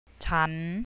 CHAHN I (FEMALE SPEAKER - INFORMAL)